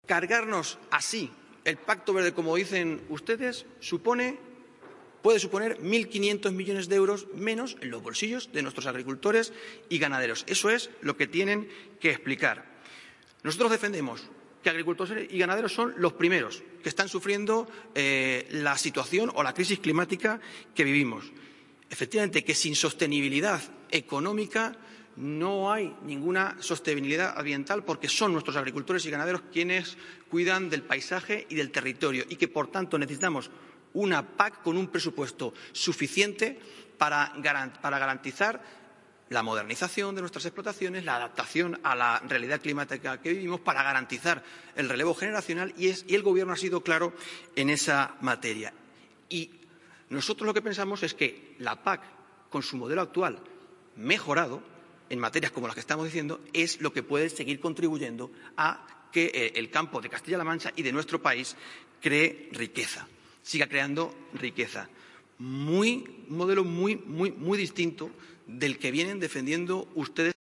Lo ha trasladado durante el Debate General sobre este Pacto en el Pleno de